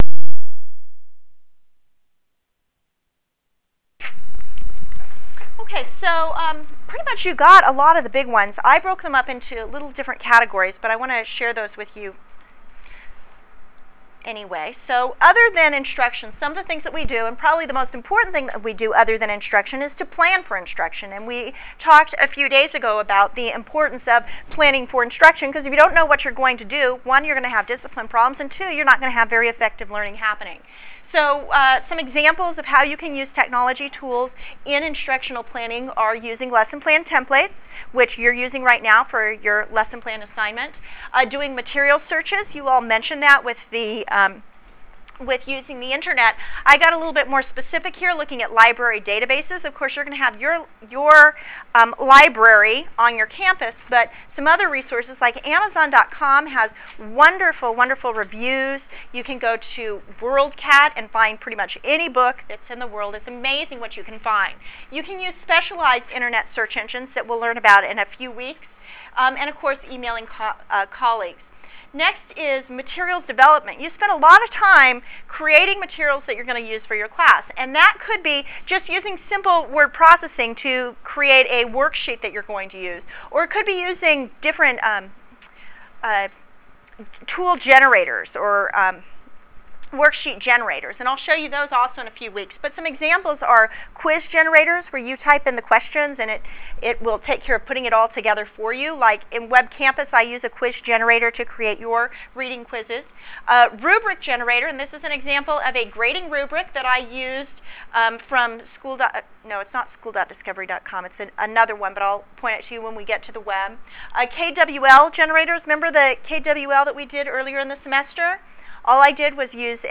Lecture_ProductivityTools9_18_06.wav